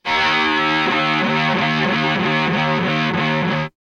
Index of /90_sSampleCDs/Roland L-CD701/GTR_Distorted 1/GTR_Power Chords